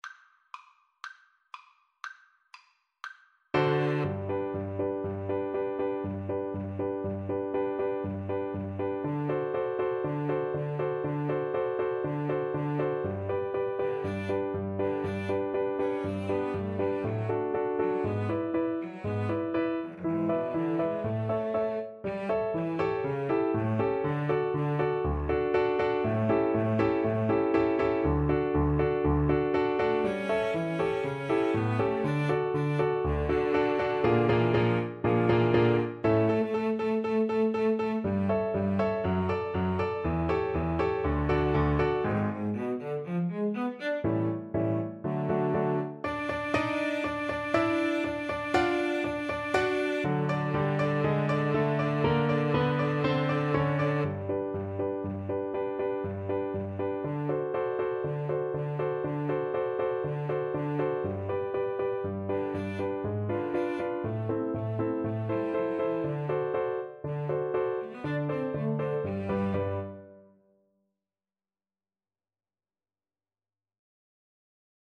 Molto allegro
Classical (View more Classical Flute-Cello Duet Music)